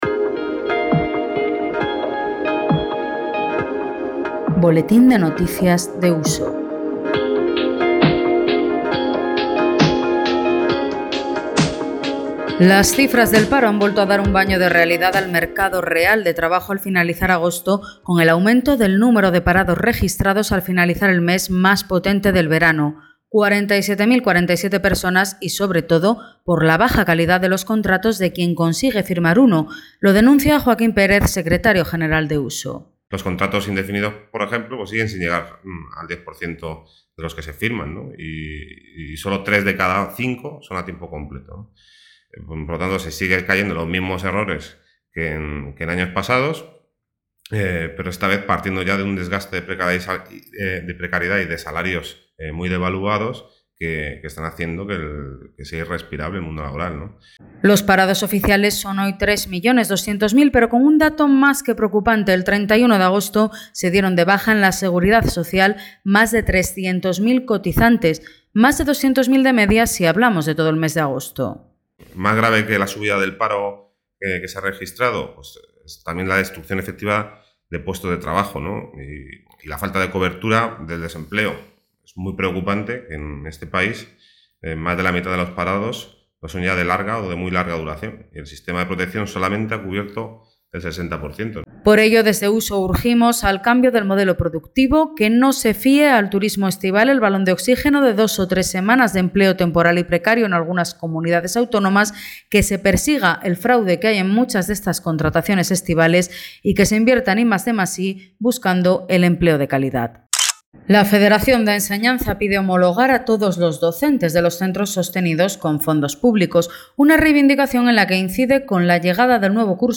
Boletín radiofónico de USO
Os adjuntamos el nuevo boletín radiofónico, correspondiente a la primera semana de septiembre.